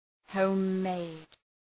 Προφορά
{,həʋm’meıd}